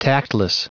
Prononciation du mot tactless en anglais (fichier audio)